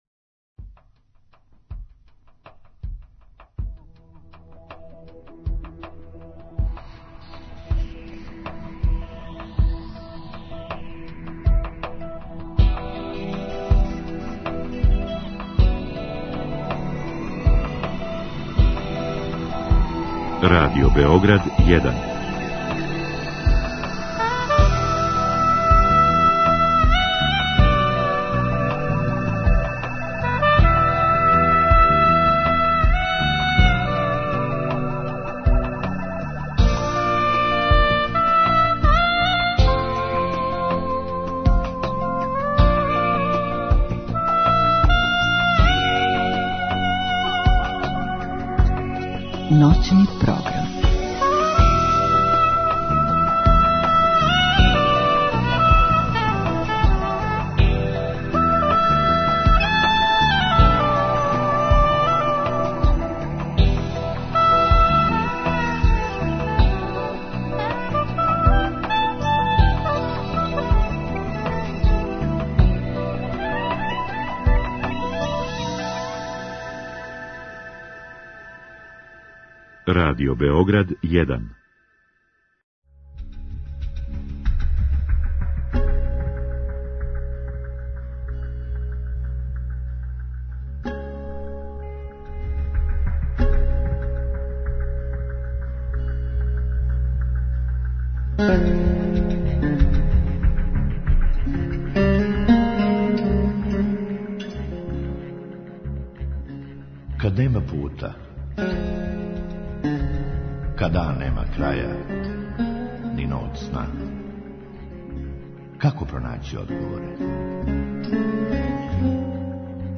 Други сат предвиђен је за укључење слушалаца, који у директном програму могу поставити питање гошћи.